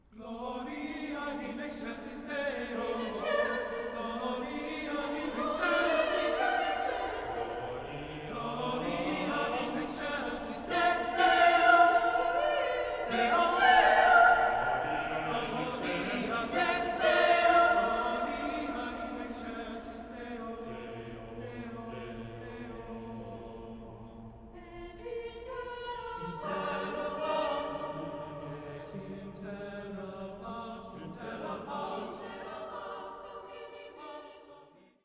Australian Sacred Choral Music